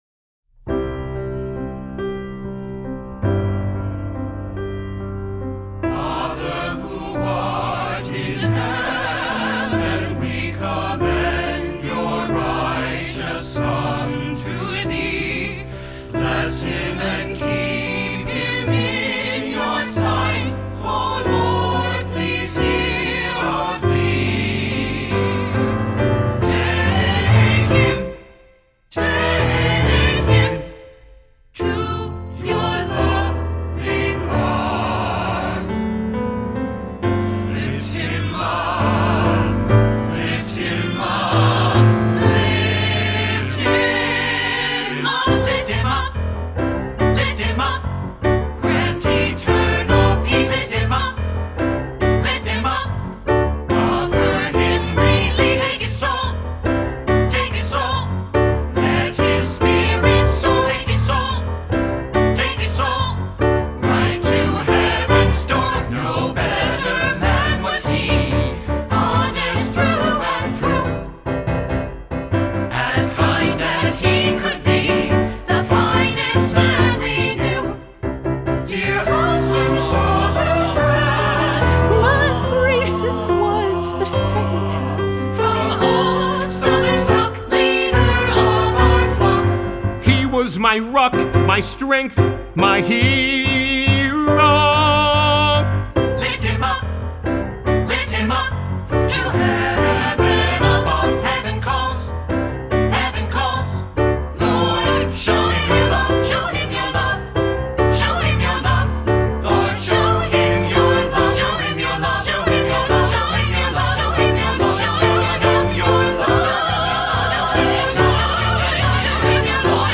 blues-infused musical
Music recorded at Eastside Sound (NYC, 2021).